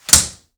METAL CLOSE.WAV